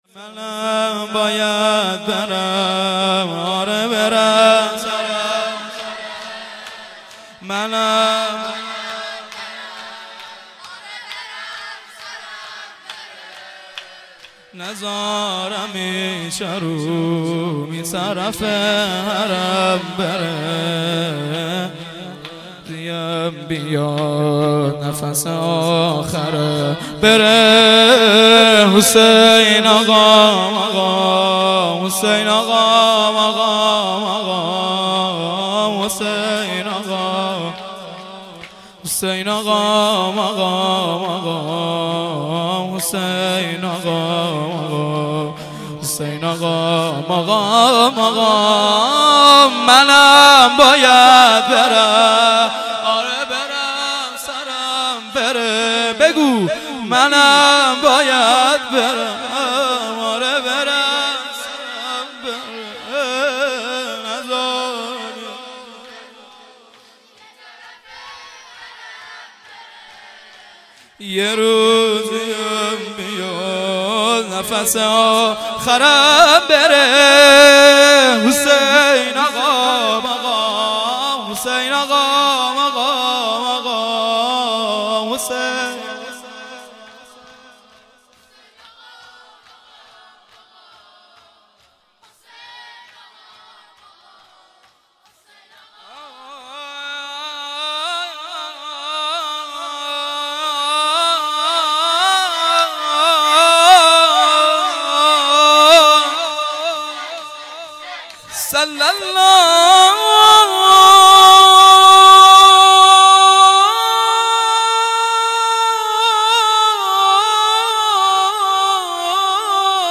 فایل های صوتی مراسم سینه زنی اولین جمعه ماه رمضان
شور[سینه به سینه از قدیم